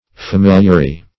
Search Result for " familiary" : The Collaborative International Dictionary of English v.0.48: Familiary \Fa*mil"ia*ry\, a. [L. familiaris.
familiary.mp3